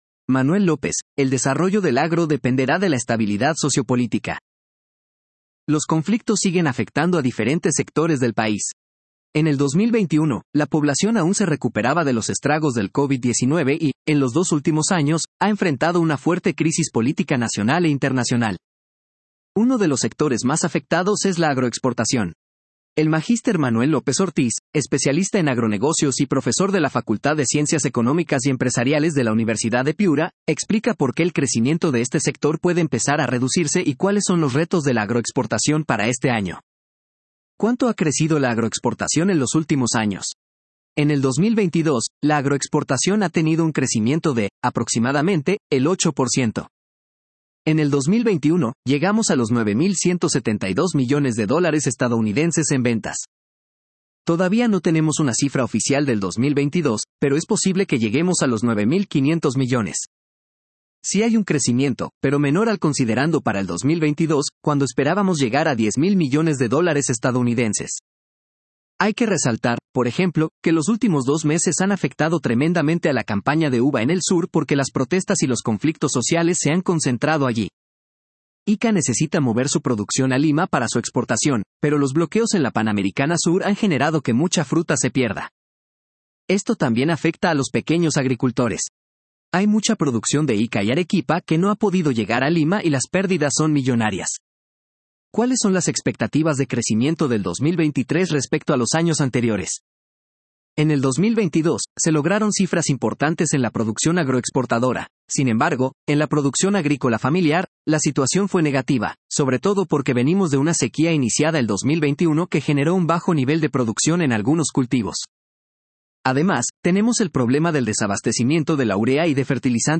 Lector implementado por DIRCOM ● Universidad de Piura